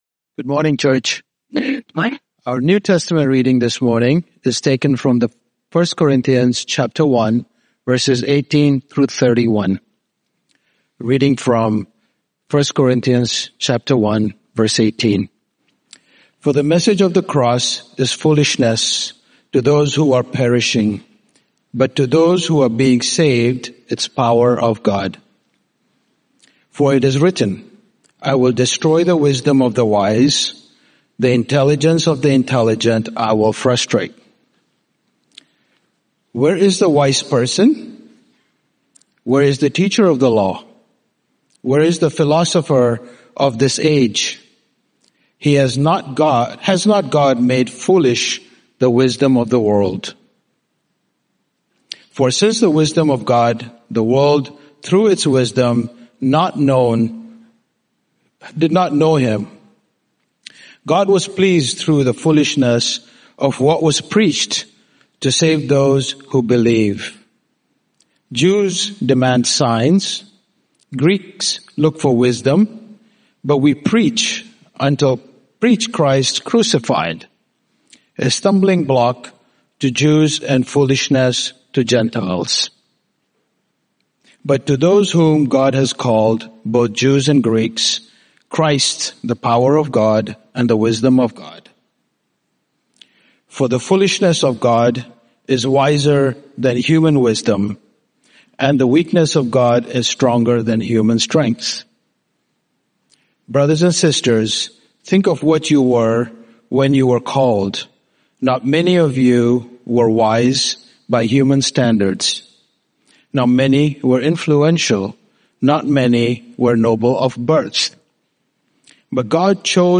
Sermon Archives - St.John's-St.Margaret's Church